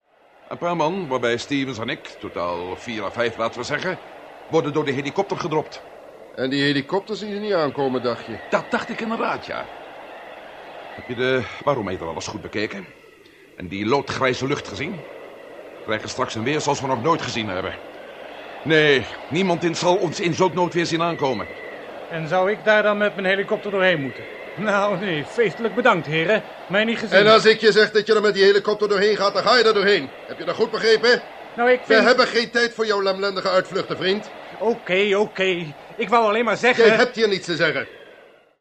te horen als de ietwat angstige helikopterpiloot
– De tunnel der duisternis – piloot